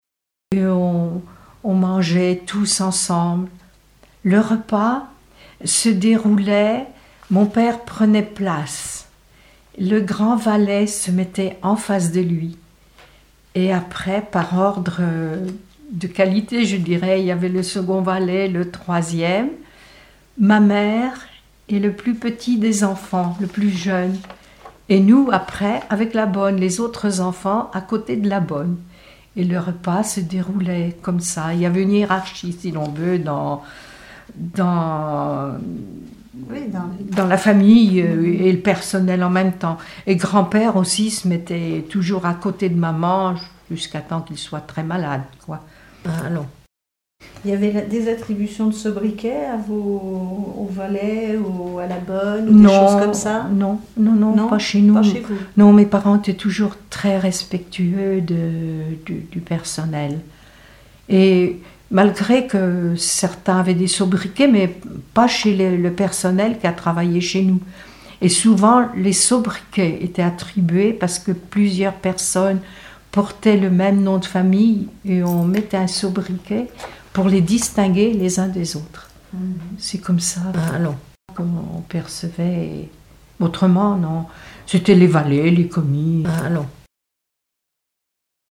témoignages
Catégorie Témoignage